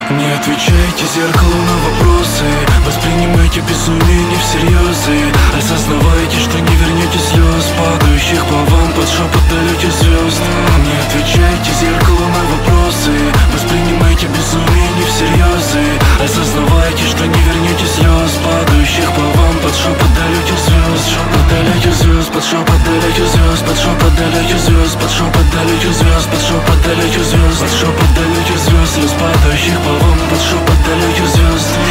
мужской голос
лирика
русский рэп